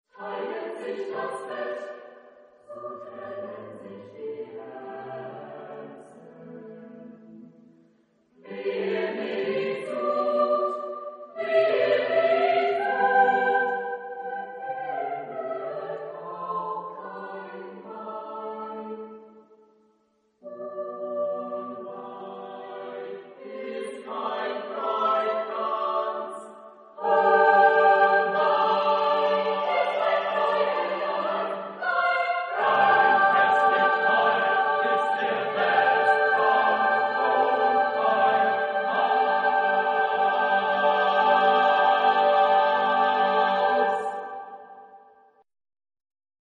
Genre-Style-Form: Cycle ; Choral song ; Secular
Type of Choir: SSAATTBB  (8 mixed voices )
Tonality: free tonality